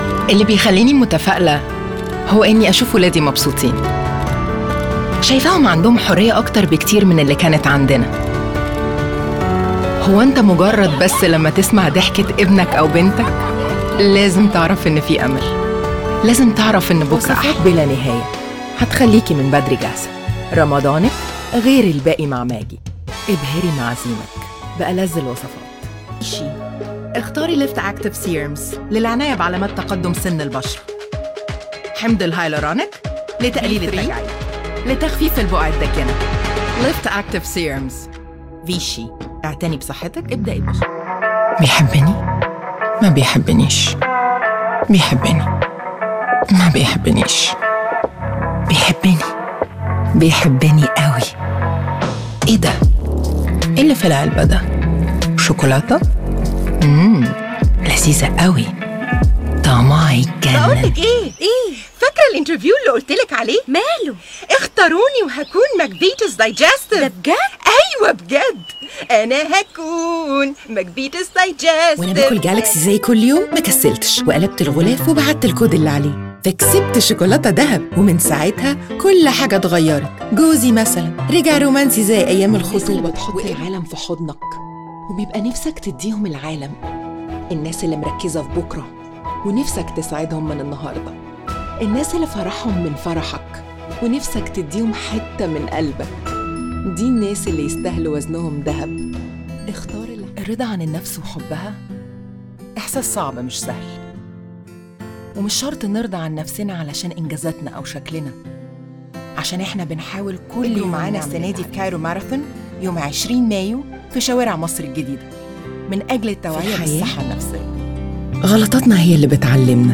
FeMale Voices